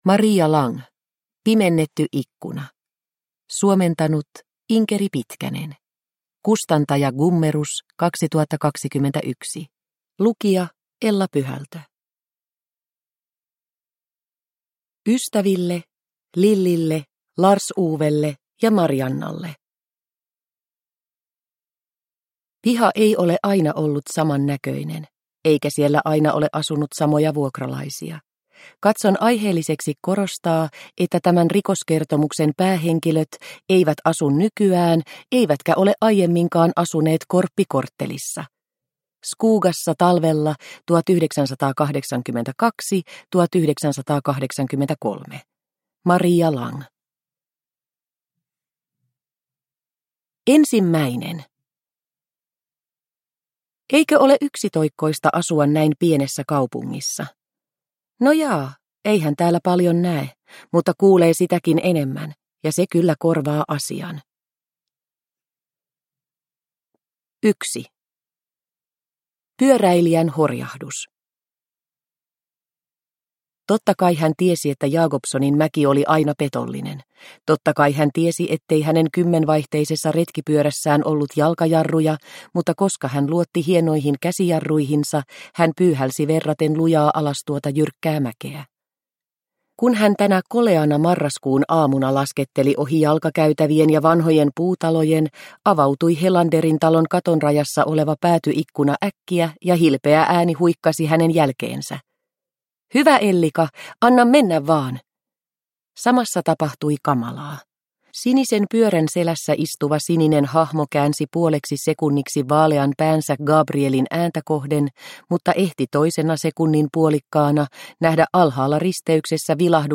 Pimennetty ikkuna – Ljudbok – Laddas ner